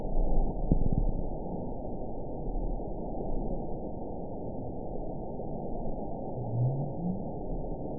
event 922342 date 12/30/24 time 01:33:30 GMT (5 months, 3 weeks ago) score 9.53 location TSS-AB04 detected by nrw target species NRW annotations +NRW Spectrogram: Frequency (kHz) vs. Time (s) audio not available .wav